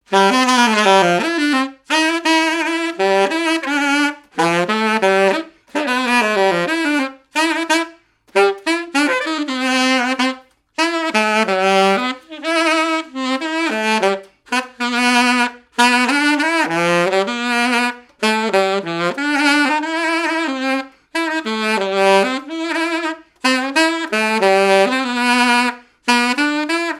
Mémoires et Patrimoines vivants - RaddO est une base de données d'archives iconographiques et sonores.
Marche de noce
activités et répertoire d'un musicien de noces et de bals
Pièce musicale inédite